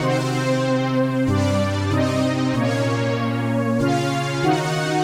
AM_VictorPad_95-C.wav